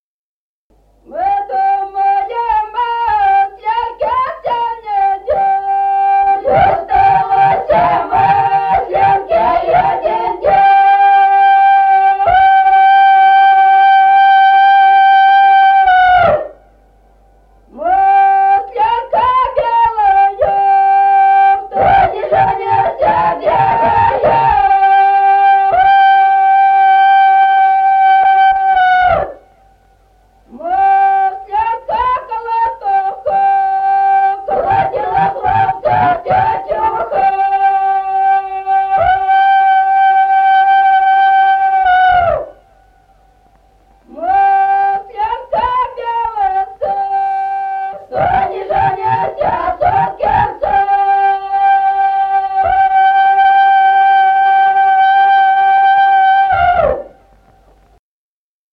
| diskname = Песни села Остроглядово.
| filedescription = Мы думали масленке (масленичная).